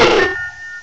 cry_not_shieldon.aif